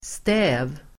Ladda ner uttalet
Uttal: [stä:v]